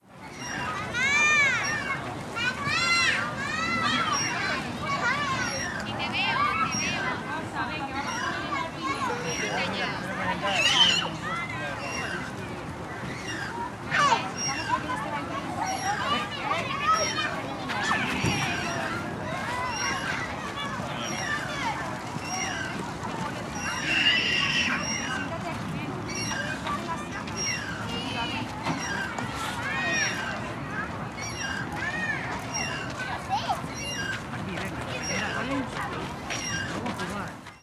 Parque de columpios con niños jugando